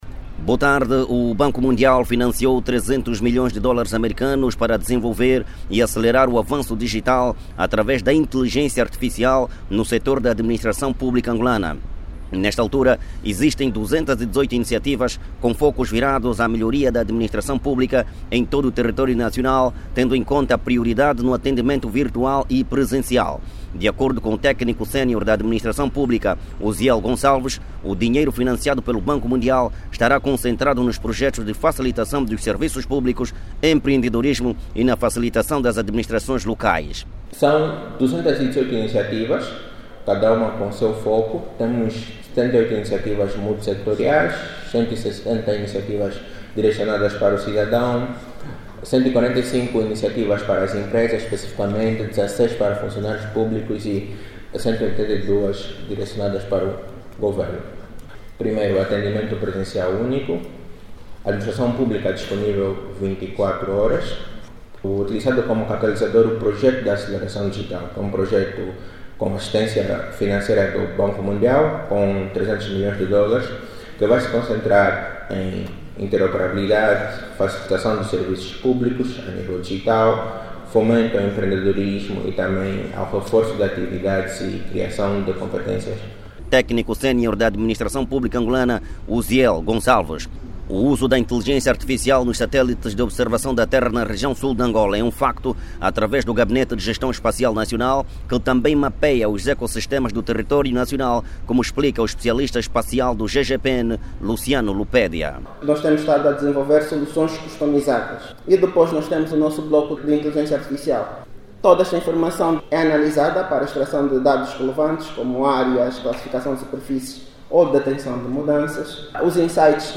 O avanço do desenvolvimento digital através da inteligência artificial em Angola, vai contar com uma linha de 300 milhões de dólares do Banco Mundial. O dinheiro será acolocado em projectos de facilitação dos serviços, empreendedorismo entre outros. Clique no áudio abaixo e saiba mais com o jornalista